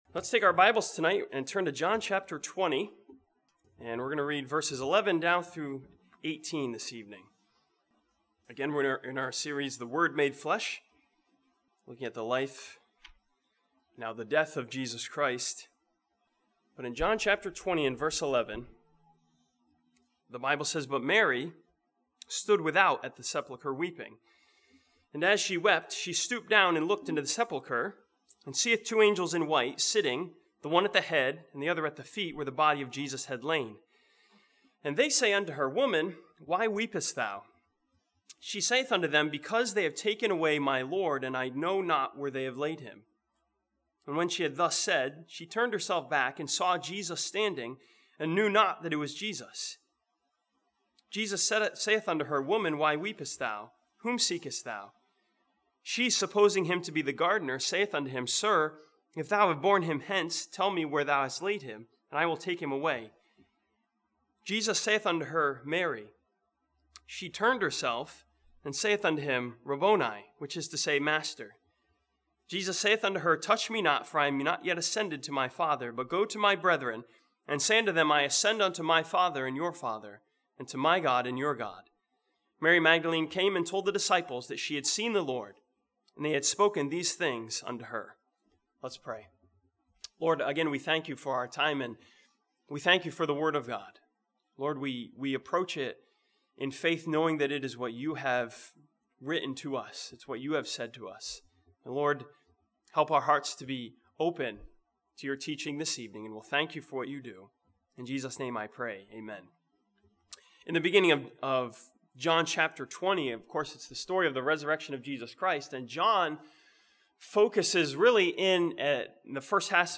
This sermon from John chapter 20 follows Mary Magdalne at the empty tomb and finds her searching for something.